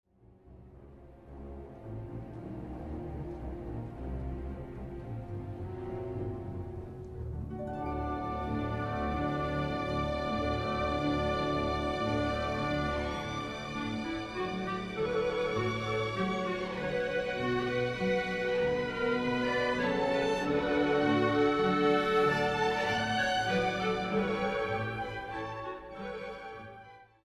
Wykonawcy świetnie akcentują groteskowy i ironiczny charakter tańca, a w wydobyciu wszystkich smakowitych detali pomaga im świetna jakość dźwięku. Przepięknie brzmi tu każda sekcja – piskliwe klarnety czy pomrukująca blacha. Także tutaj tempo jest szybkie, a oszczędnie stosowane rubato akcentuje taneczny charakter muzyki.